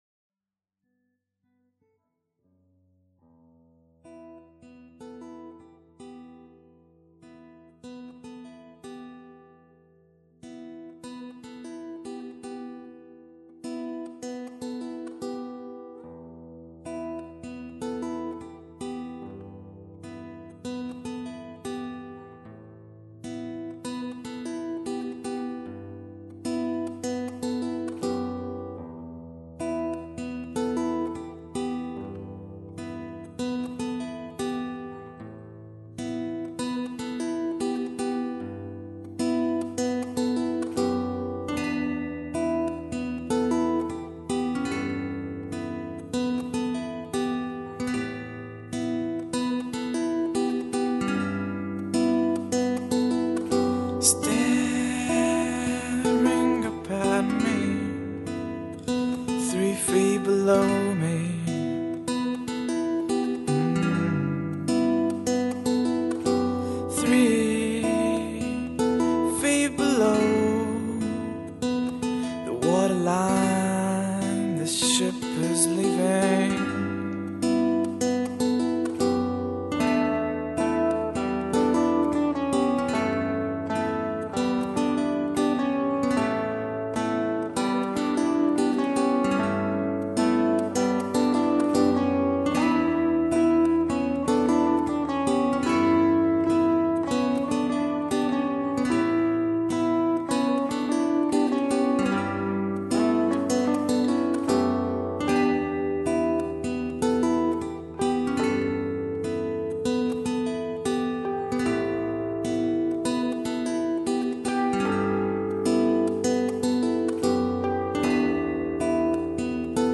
voz, guitarra, teclas, sintetizadores, percussão